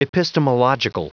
Prononciation du mot epistemological en anglais (fichier audio)
epistemological.wav